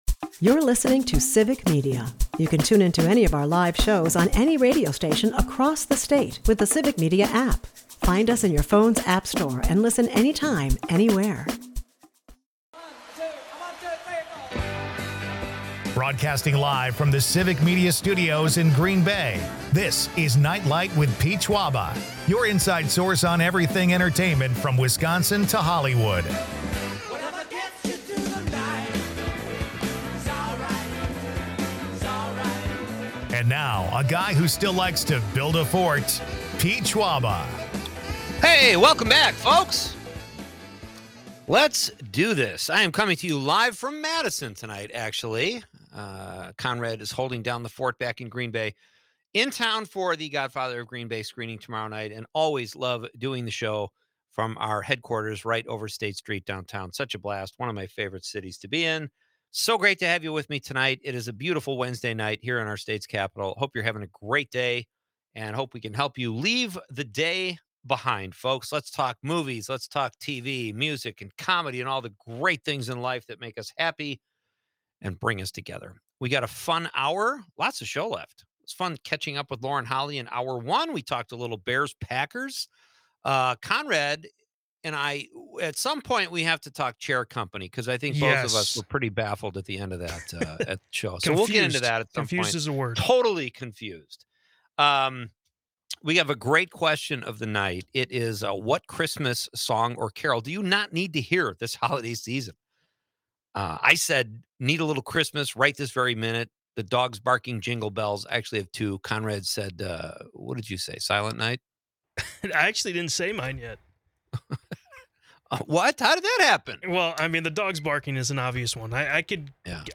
It's a night of laughs, pastries, and holiday cheer!